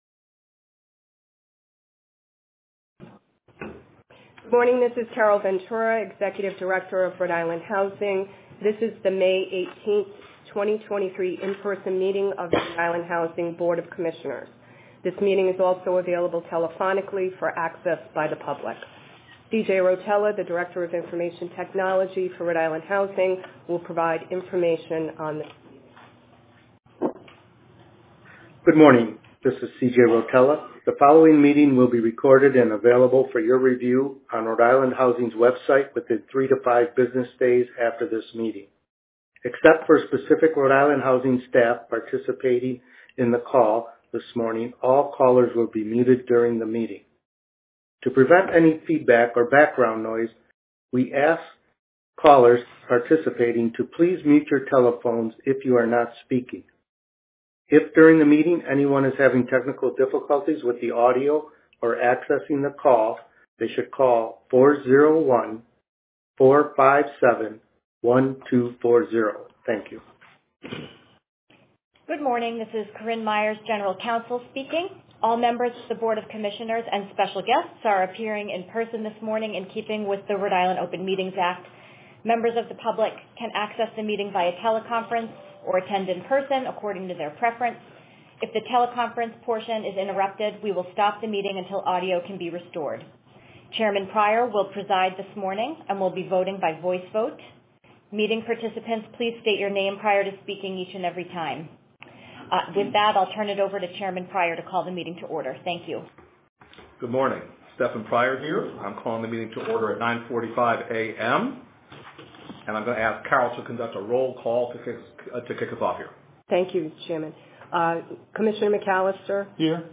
Recording of RIHousing Board of Commissioners Meeting: 05.18.2023